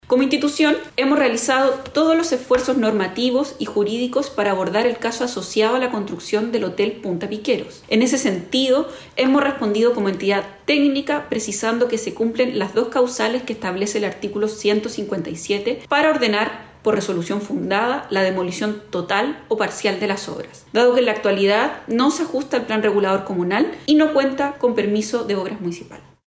La seremi del Minvu en Valparaíso, Belén Paredes, aseguró que las obras del Hotel Punta Piqueros no se ajustan al plan regulador comunal de Concón y no cuentan con permiso de obras municipales.